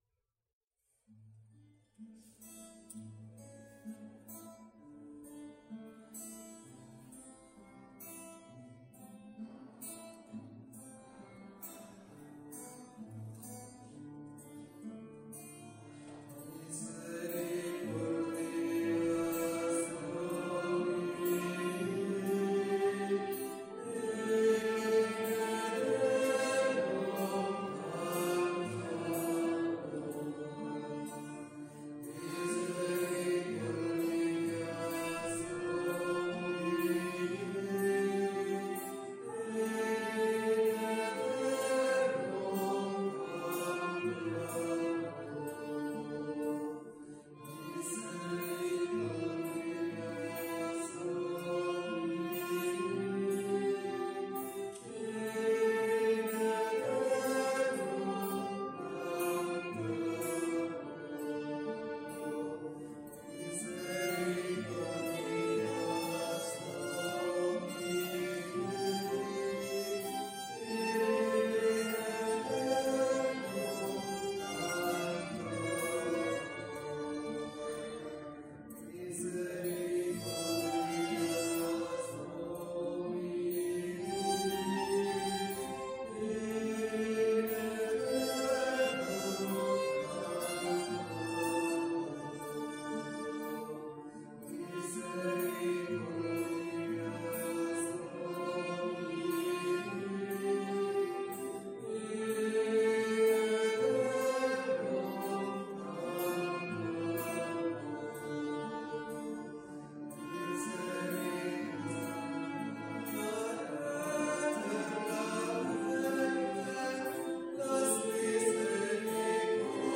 Pregària d'octubre
Església de Santa Anna - Diumenge 24 d'octubre de 2021
Vàrem cantar...